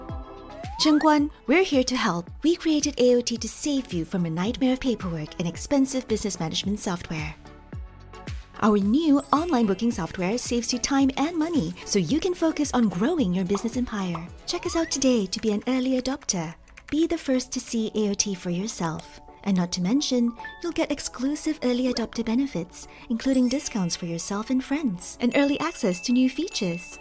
~ Uma dubladora calorosa, amigável e versátil com apelo global ~
Microfone Rode NT1 (Kit de estúdio NT1 AI-1 com interface de áudio), suporte antichoque SM6, fones de ouvido Shure SRH440A Gen 2, filtro pop, escudo de isolamento, manta de amortecimento de som e placas de feltro